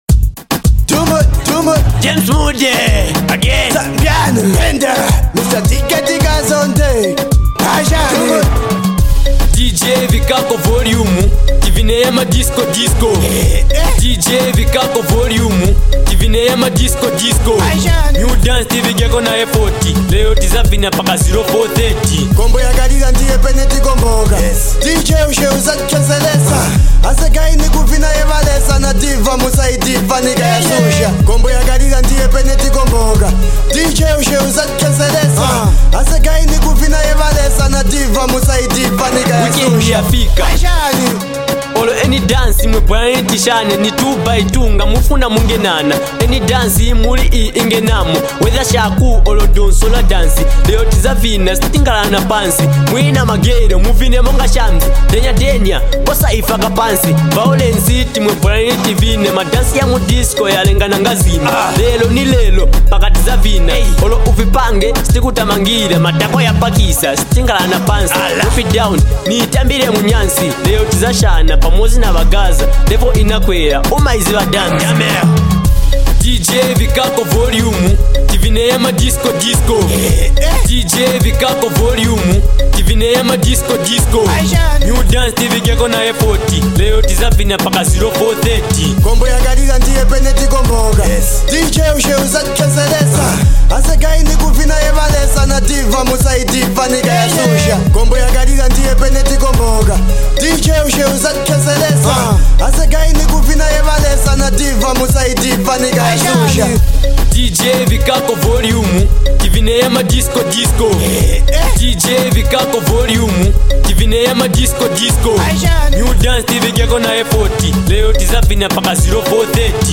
dance-hall tune